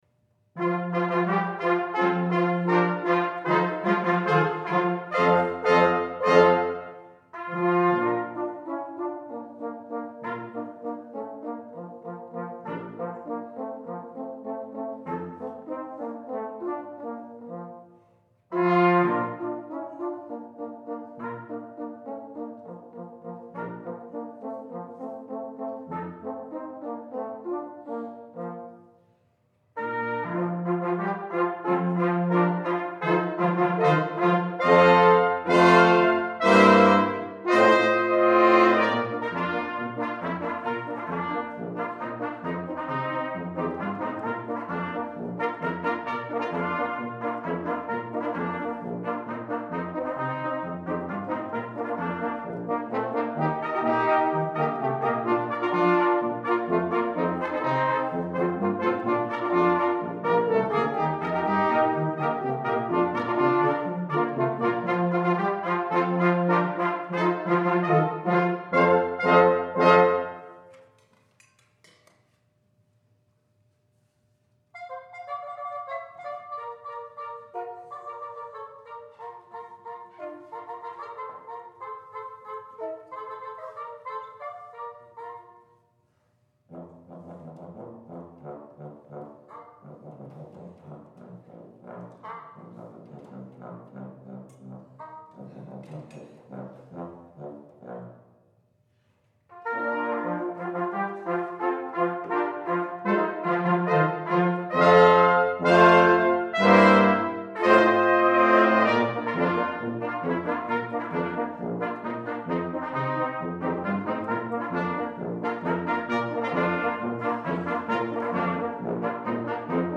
for Brass Quintet (2005)